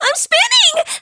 p4u-yukari-spin.wav